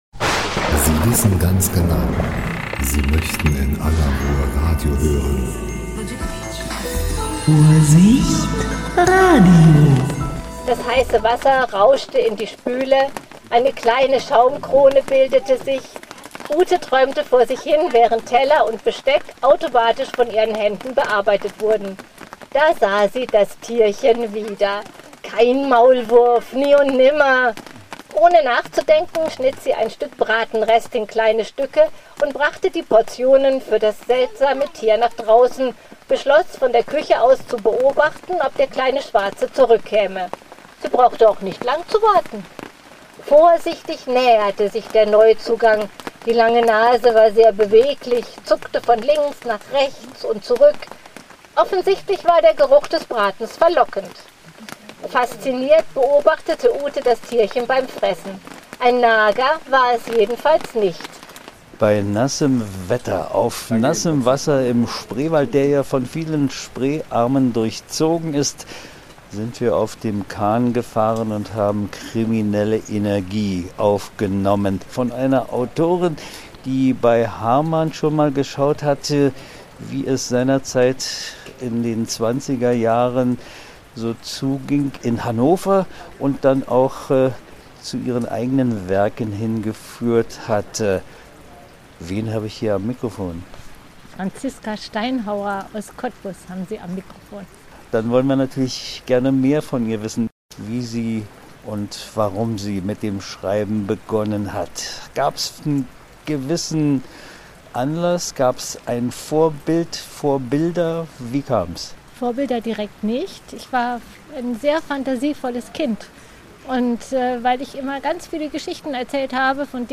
Interview 15.10.2025